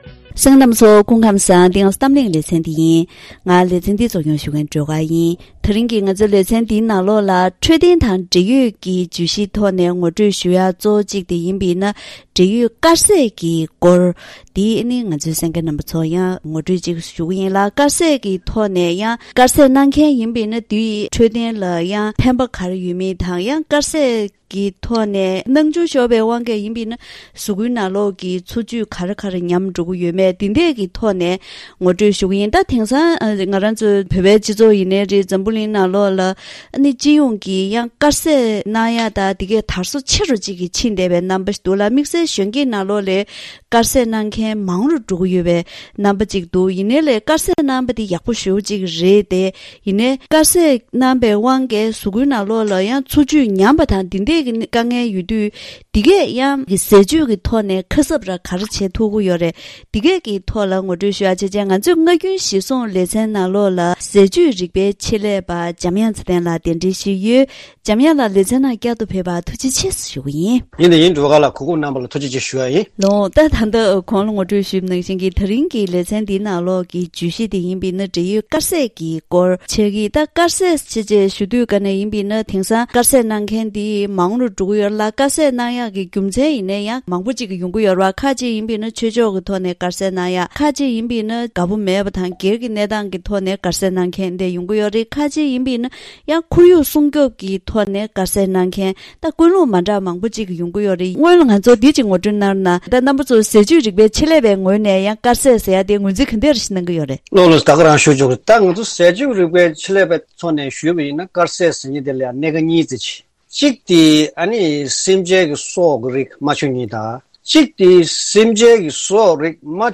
ཐེངས་འདིའི་གཏམ་གླེང་ཞལ་པར་ལེ་ཚན
ཟས་བཅུད་རིག་པའི་ཆེད་ལས་པ་དང་ལྷན་དུ